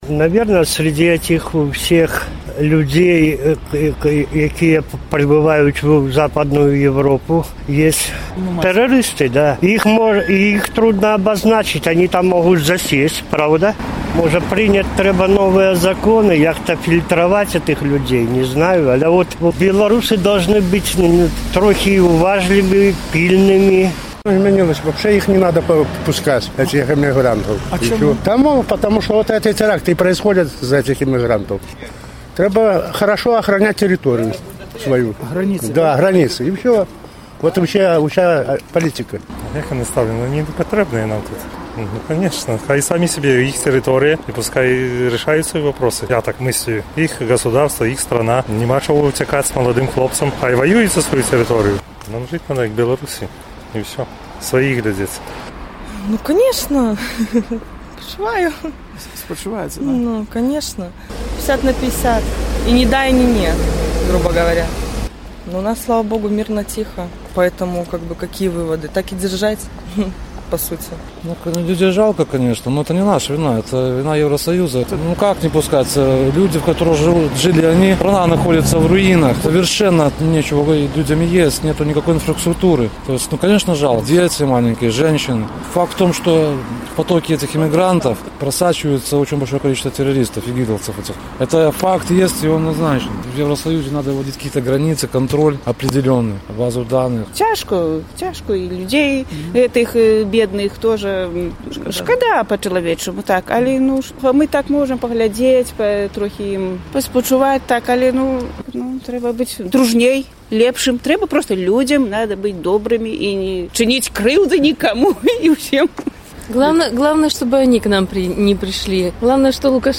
«Нам трэба быць дабрэйшымі адзін да аднаго», — апытаньне ў Горадні
Ці паўплывалі драматычныя падзеі ў Парыжы на ваша стаўленьне да мігрантаў з Усходу? Якія высновы пасьля гэтых падзеяў павінна зрабіць Беларусь? З такімі пытаньнямі наш карэспандэнт зьвяртаўся да гарадзенцаў.